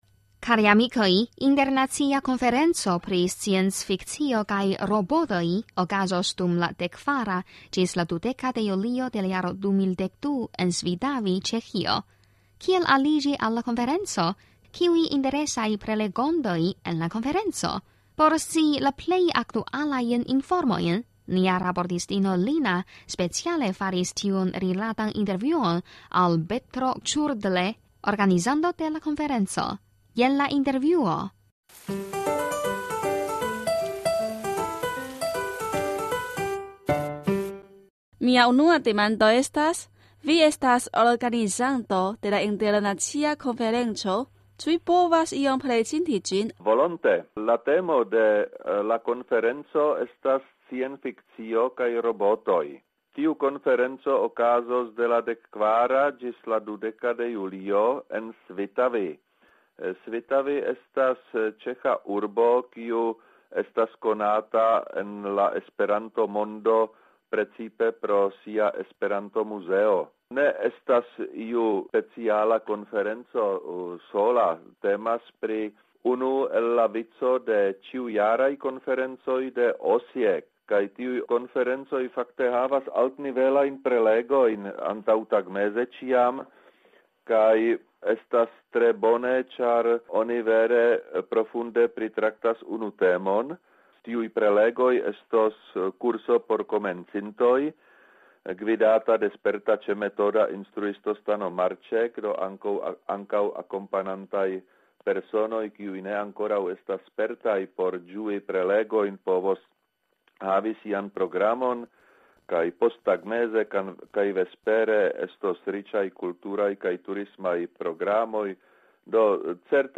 intervjuon